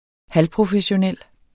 Udtale [ ˈhal- ]